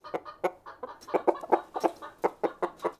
chicken-heck-sound